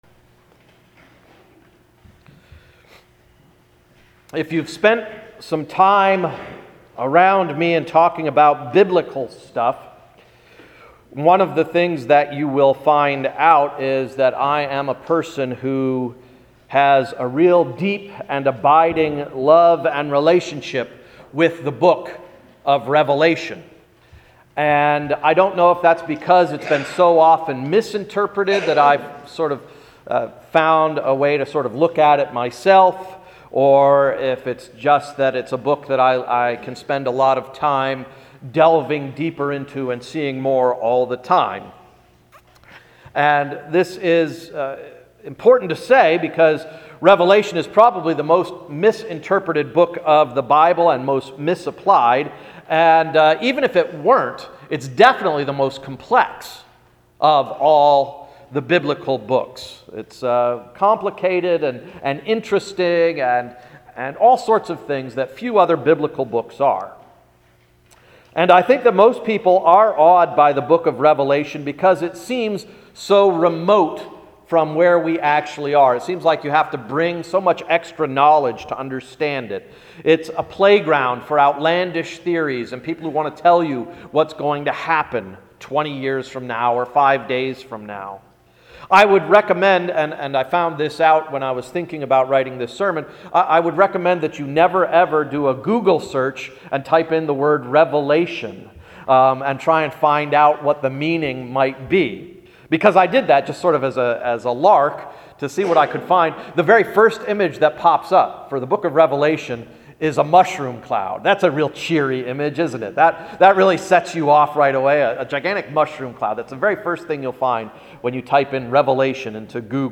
Sermon of April 7th–“Nice to Meet You”